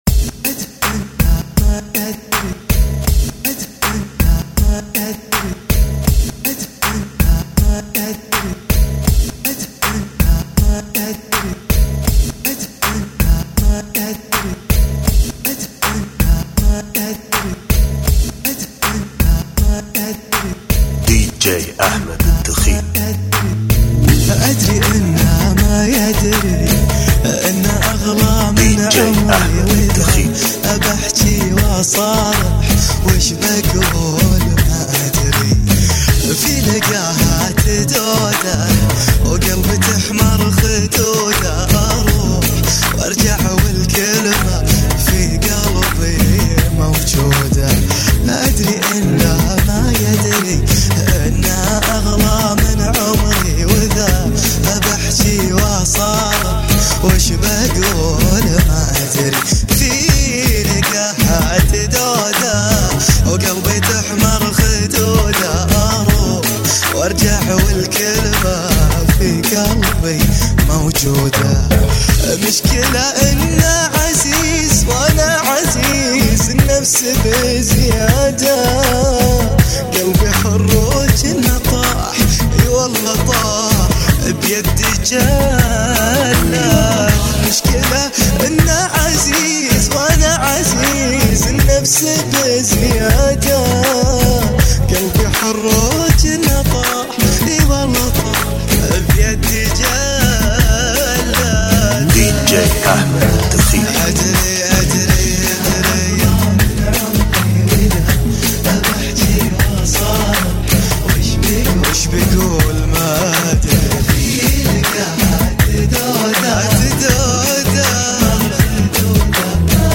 Funky Bpm 80